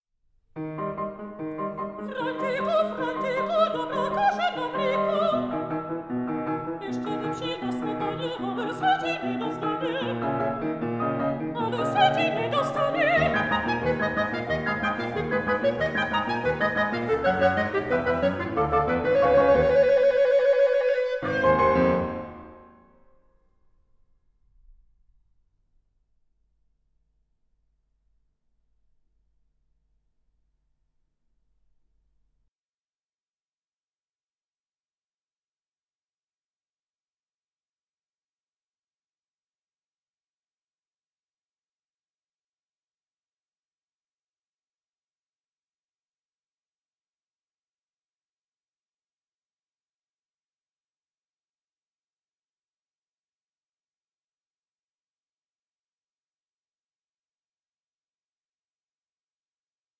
AN EVENING OF INTIMATE SONGS AMONG FRIENDS
mezzo-soprano